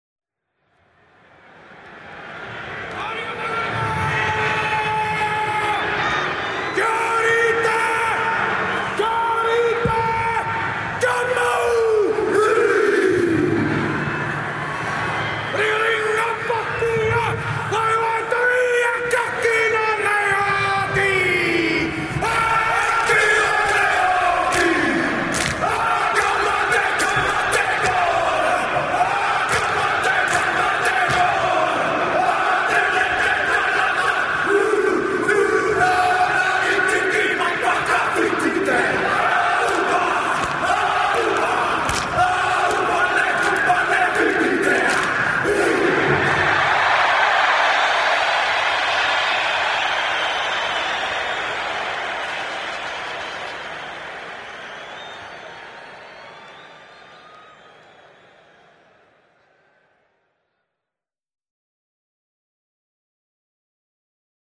here is the famous boys doing their haka at the beginning of a match
NewZealandAllBlacks-Haka.mp3